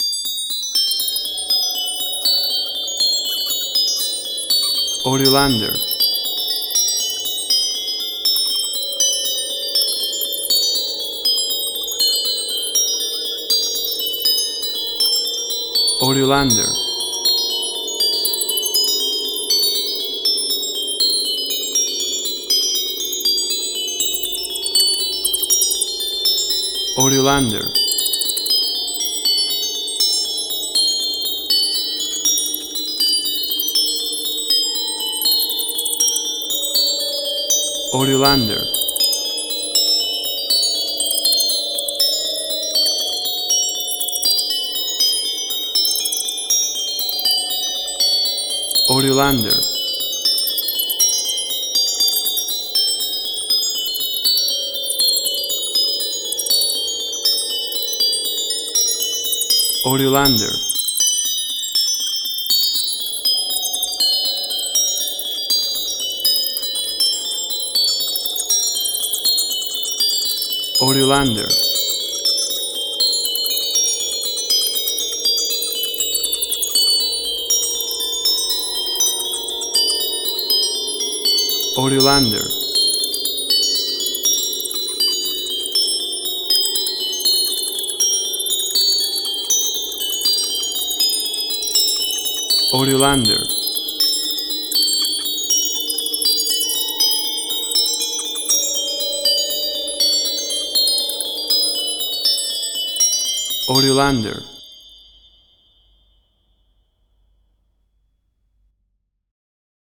WAV Sample Rate: 24-Bit stereo, 48.0 kHz